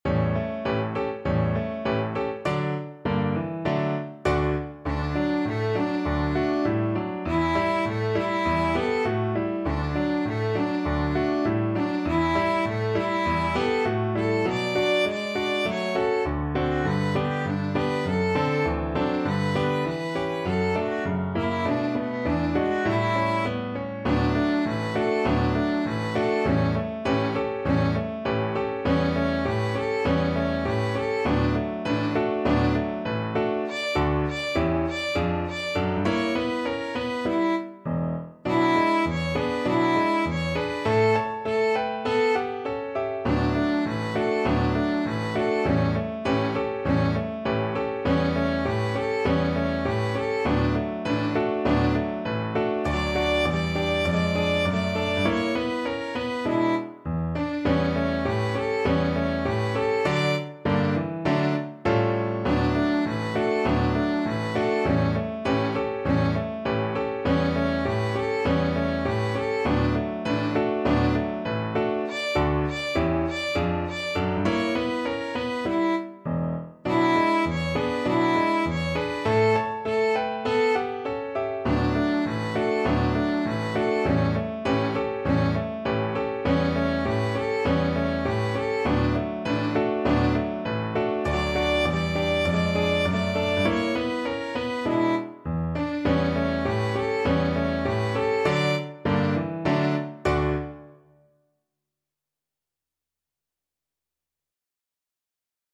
Violin version
Moderato =c.100
2/2 (View more 2/2 Music)
Pop (View more Pop Violin Music)